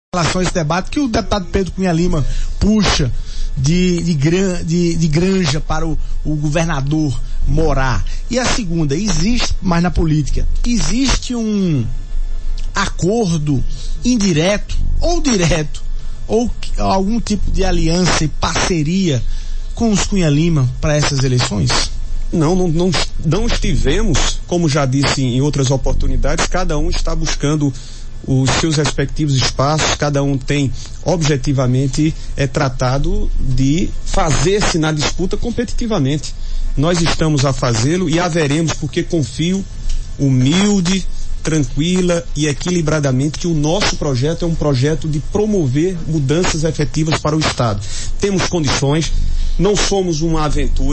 Pré-candidato ao Governo da Paraíba pelo MDB, o senador Veneziano Vital negou, em entrevista nesta sexta-feira (15), qualquer existência de acordo direto ou indireto, aliança ou parceria com o grupo Cunha Lima para as eleições desse ano.